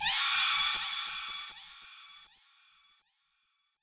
MB Vox (8).wav